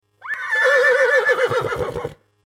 جلوه های صوتی
دانلود صدای اسب 12 از ساعد نیوز با لینک مستقیم و کیفیت بالا